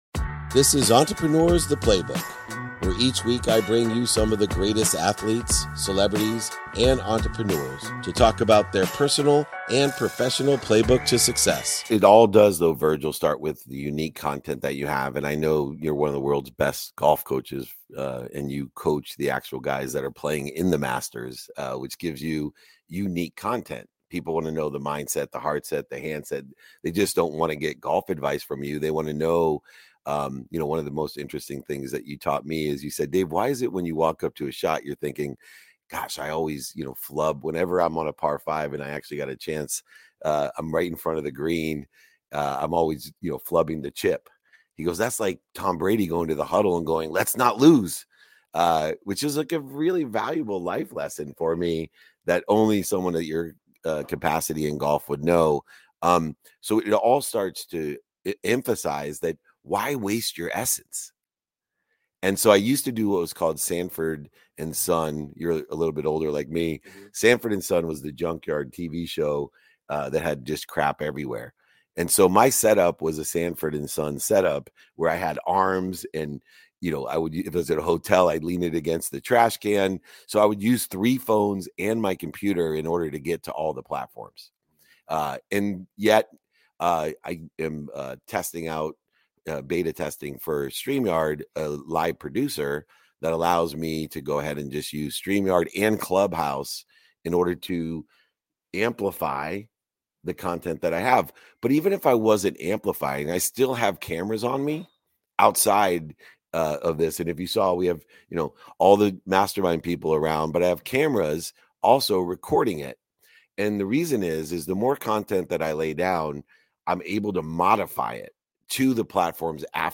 In today's episode, I engage in a dynamic Q&A session, offering personal and professional insights in response to a variety of questions from our audience.